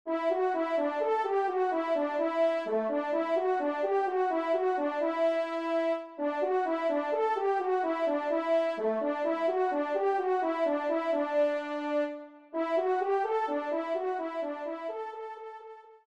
Trompe Solo (TS)